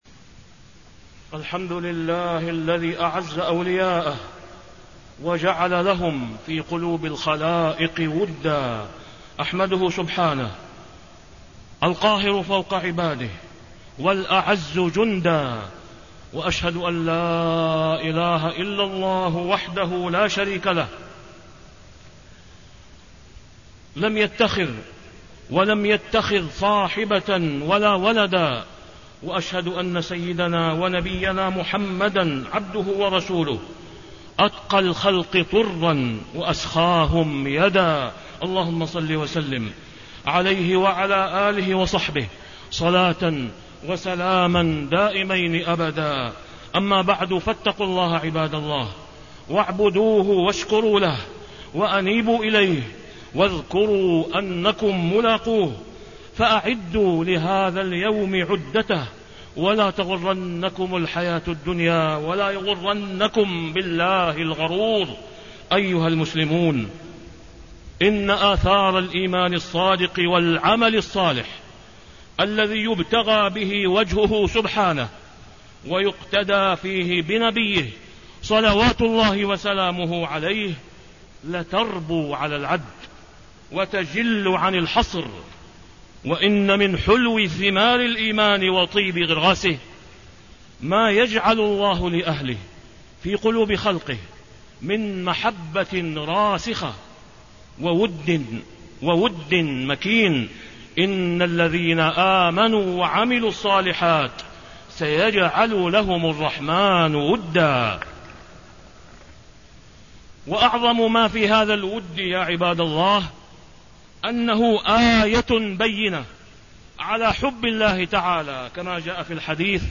تاريخ النشر ٢ جمادى الآخرة ١٤٣٤ هـ المكان: المسجد الحرام الشيخ: فضيلة الشيخ د. أسامة بن عبدالله خياط فضيلة الشيخ د. أسامة بن عبدالله خياط منزلة الصحابة رضي الله عنهم في الكتاب والسنة